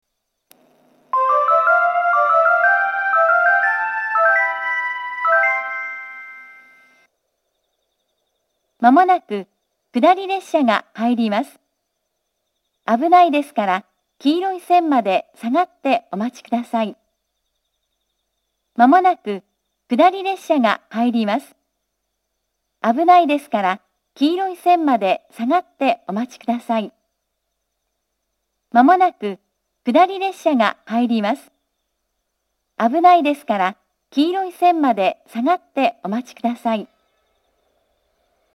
接近放送は秋田支社の新幹線並行区間標準のものが使用されています。
１番線下り接近放送
jinguji-1bannsenn-kudari-sekkinn.mp3